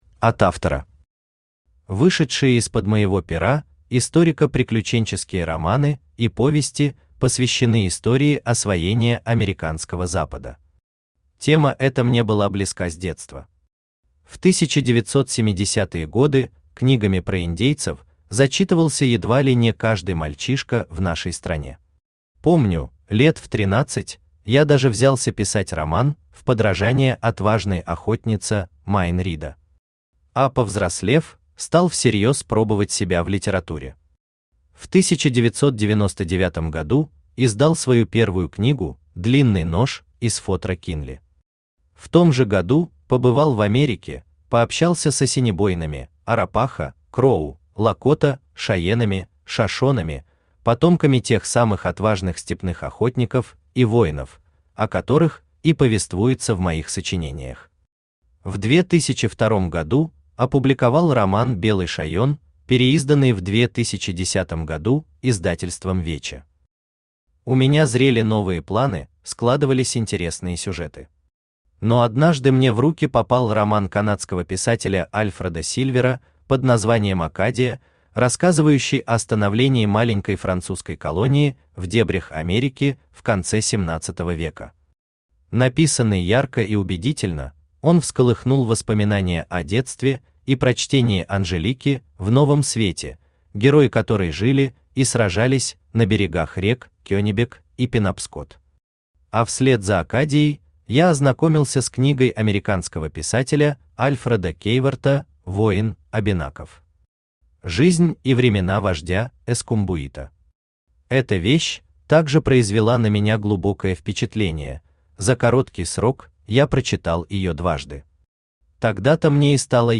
Aудиокнига В тени томагавка, или Русские в Новом Свете Автор Сергей Дмитриевич Юров Читает аудиокнигу Авточтец ЛитРес.